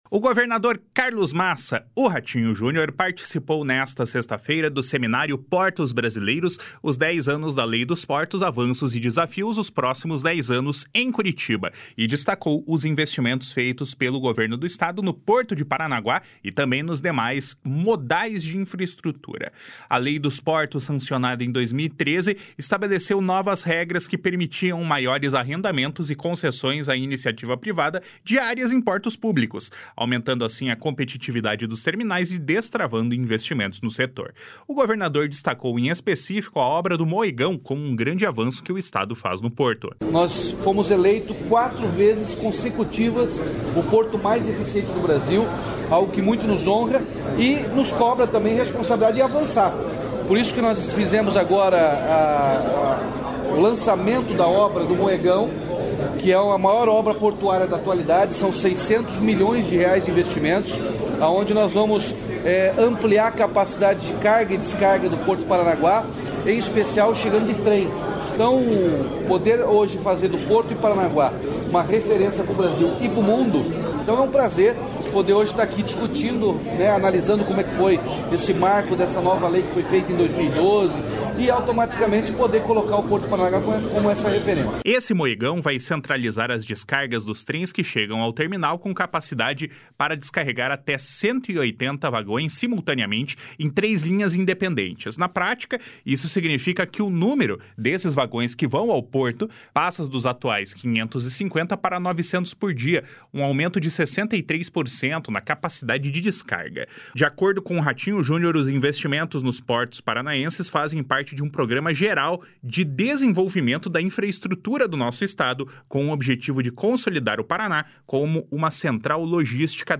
O governador Carlos Massa Ratinho Junior participou nesta sexta-feira do seminário “Portos Brasileiros – Os Dez Anos da Lei dos Portos: avanços e desafios, os próximos dez anos”, em Curitiba, e destacou os investimentos feitos pelo Governo do Estado no Porto de Paranaguá e nos demais modais de infraestrutura.
O governador destacou em específico a obra do Moegão como um grande avanço que o Estado faz no Porto. // SONORA RATINHO JUNIOR //